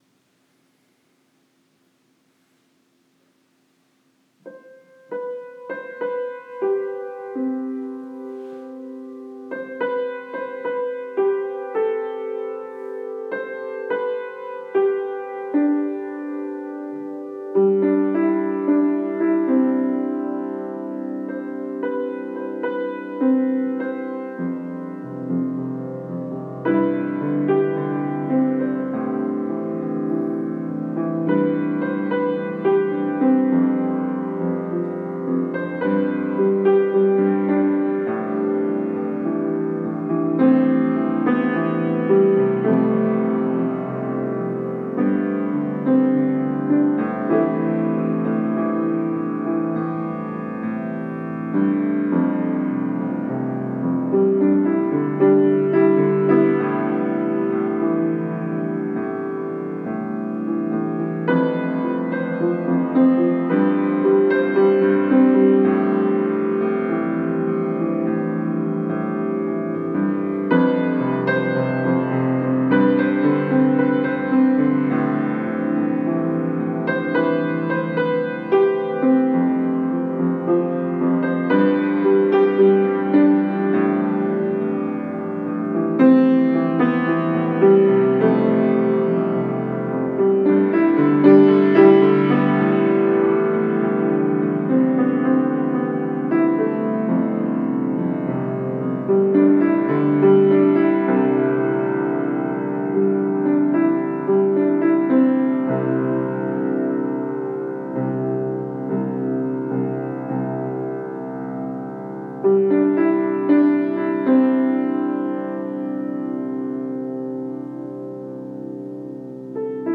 COSMOS ALBUM MEDITATION MUSIC